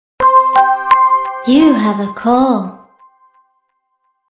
Cŕŕn You have a call - žensky hlas 0:04